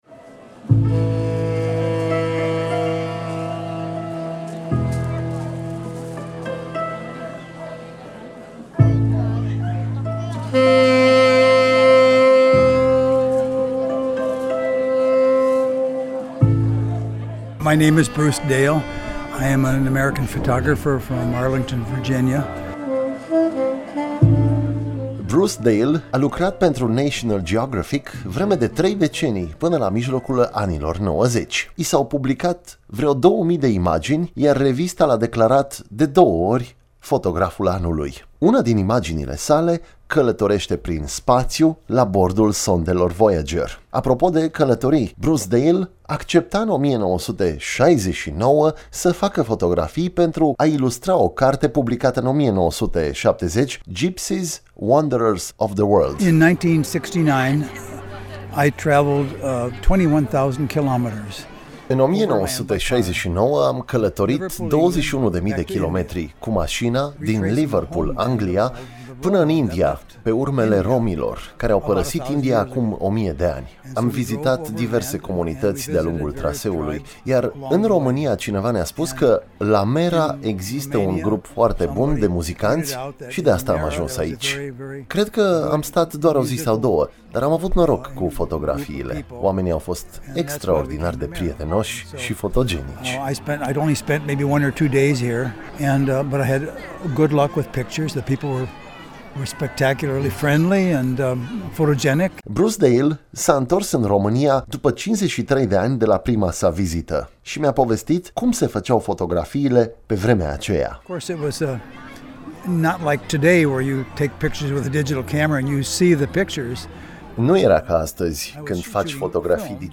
Bruce Dale a revenit în week-endul trecut la Mera, acolo unde a avut loc un festival dedicat muzicii lumii – Mera World Music Festival. A expus pozele făcute acum 53 de ani și a încercat să ia legatura cu personajele imortalizate atunci pentru cartea Gipsyes – Wanderers Of The World.